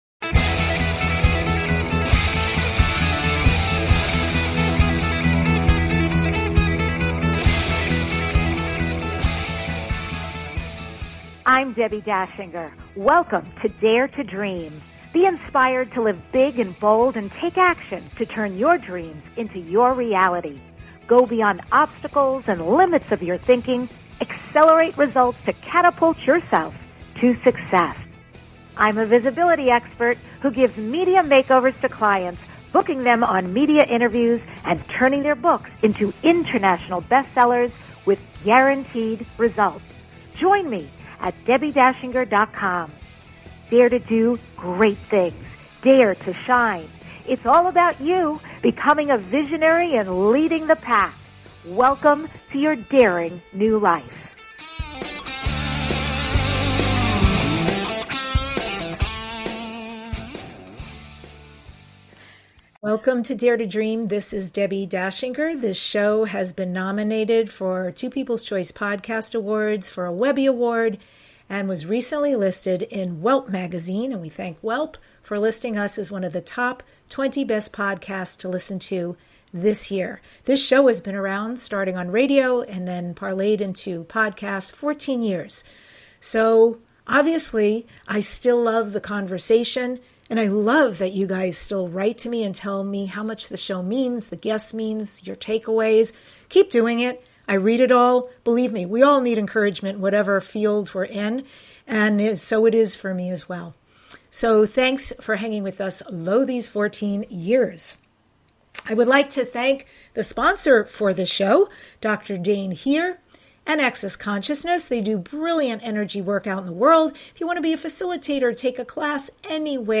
Talk Show Episode
The award-winning DARE TO DREAM Podcast is your #1 transformation conversation.